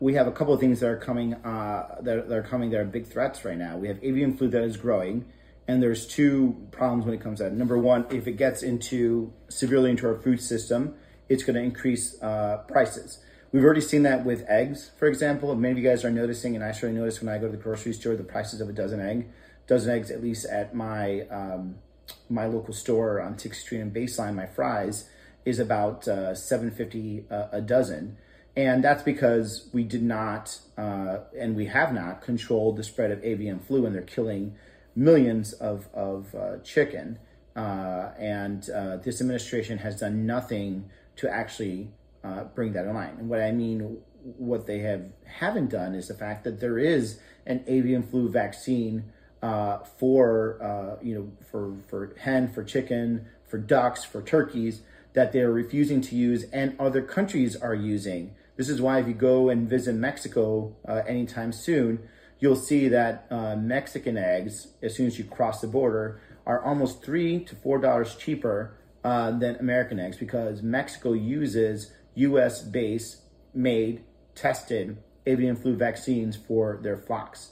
PHOENIX – Last night, Senator Ruben Gallego (D-AZ) spoke to over 3,000 Arizonans during his first telephone town hall.
On the call, he explained why it matters.